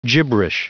Prononciation du mot gibberish en anglais (fichier audio)
Prononciation du mot : gibberish